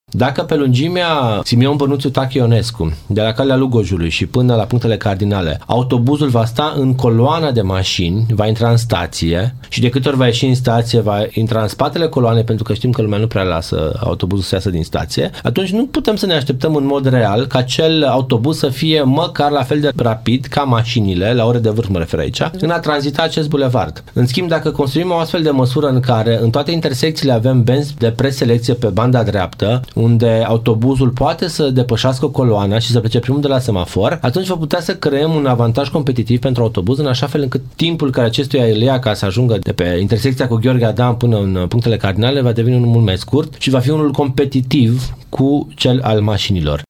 Viceprimarul Ruben Lațcău spune că aproximativ 14.000 de călători, care circulă zilnic vor ajunge mai repede la destinație.